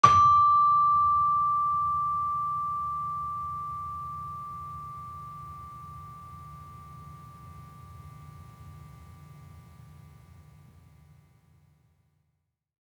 Gamelan Sound Bank
Gender-3-D5-f.wav